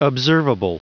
Prononciation du mot observable en anglais (fichier audio)
Prononciation du mot : observable